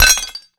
grenade_hit_metal_03.WAV